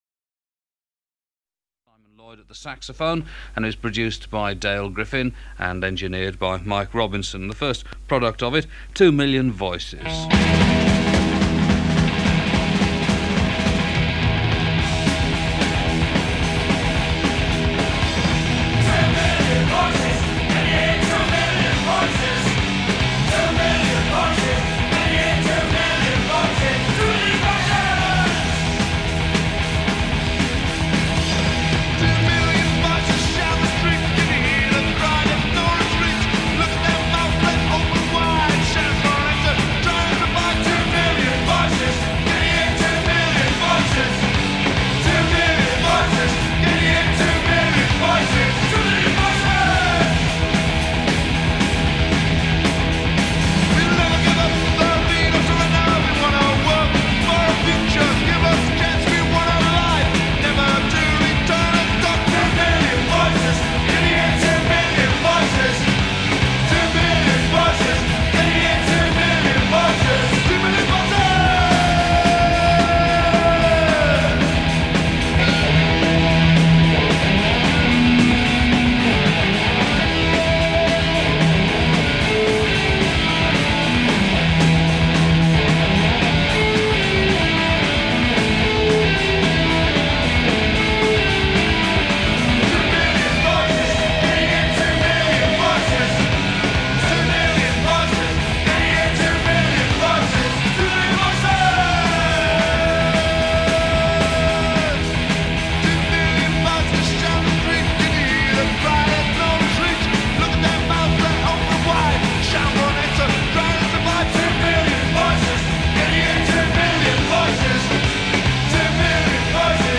Oi! punk subgenre